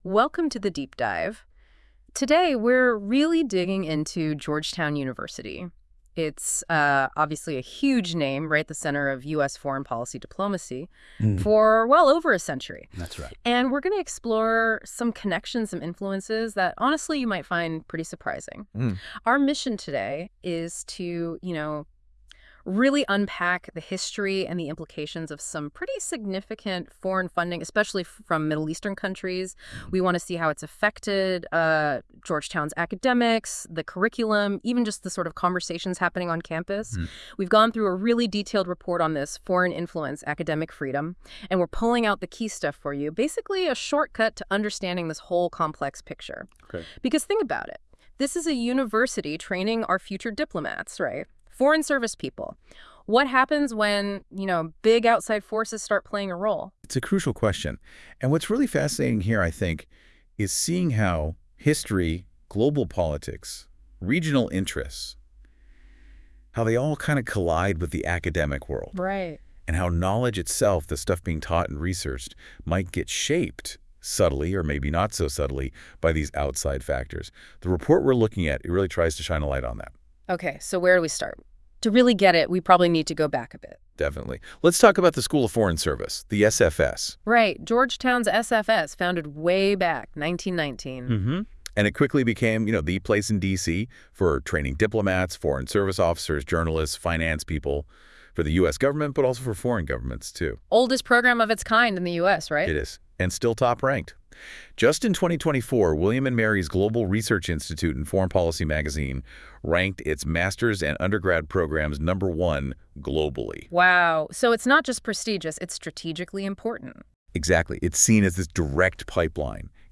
*This audio was created by NotebookLM, using text provided solely by the report, Foreign Infiltration.